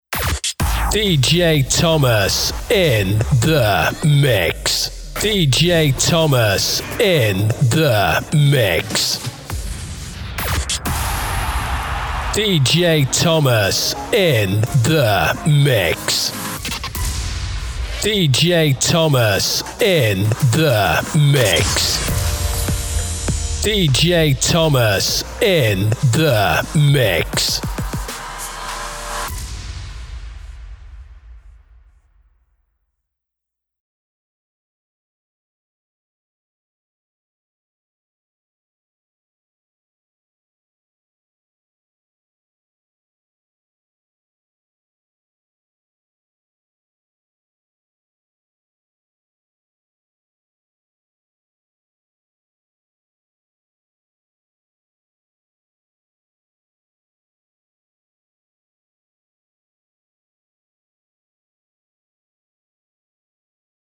Version 3: DJ Drops
djdropsdemo.mp3